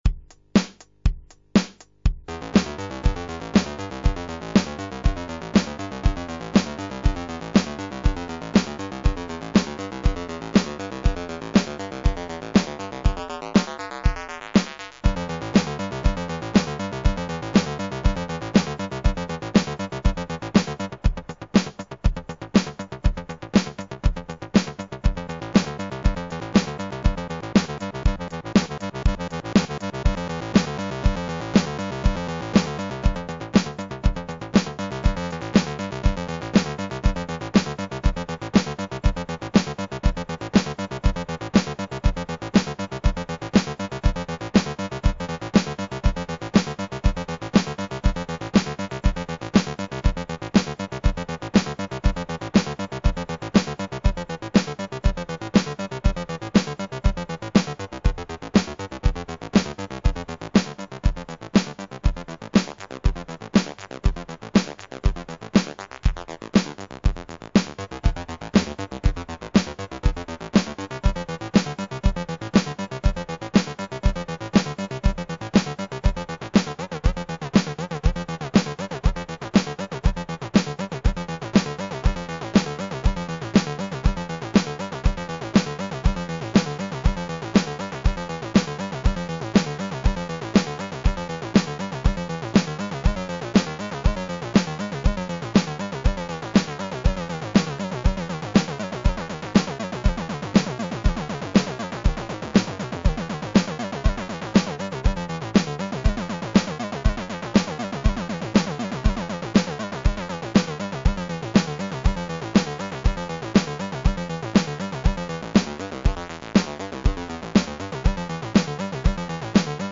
AVR Synth